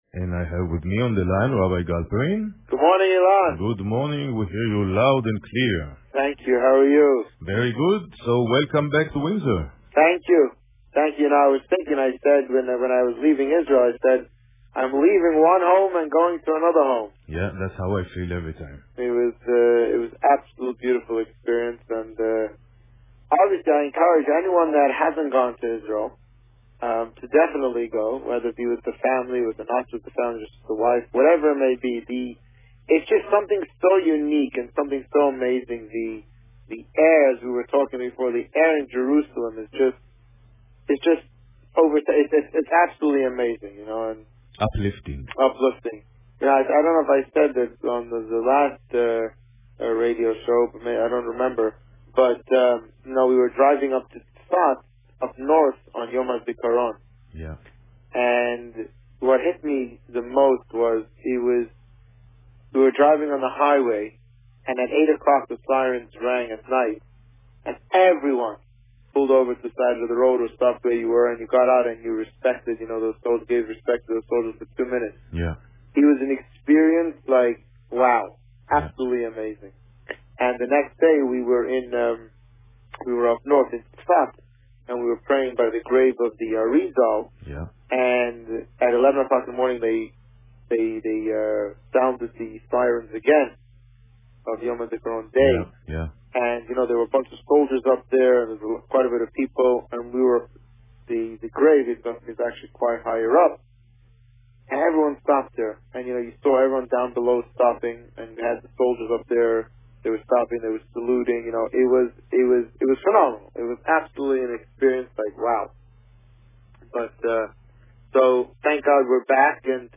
This week, the Rabbi is back from his vacation in Israel, and spoke about the "second" Pesach.  Listen to the interview